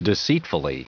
Prononciation du mot deceitfully en anglais (fichier audio)
Prononciation du mot : deceitfully